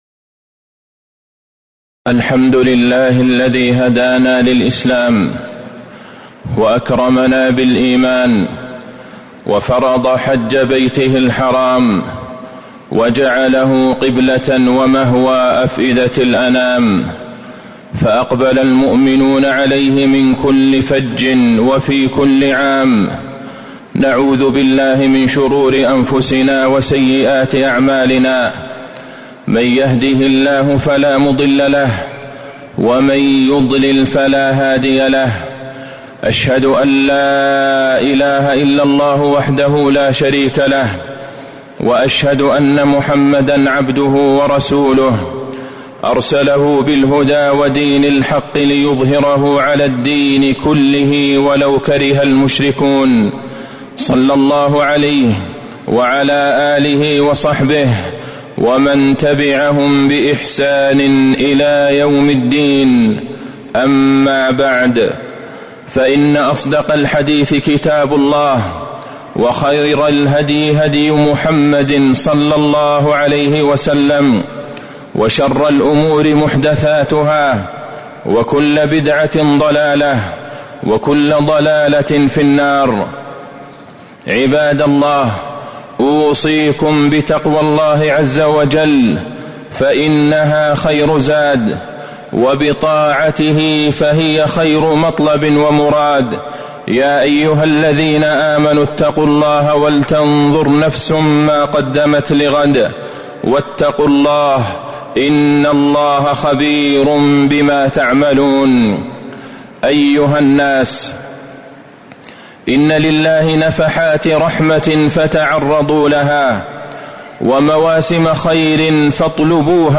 تاريخ النشر ١ ذو الحجة ١٤٤٥ هـ المكان: المسجد النبوي الشيخ: فضيلة الشيخ د. عبدالله بن عبدالرحمن البعيجان فضيلة الشيخ د. عبدالله بن عبدالرحمن البعيجان عشر ذي الحجة موسم الخيرات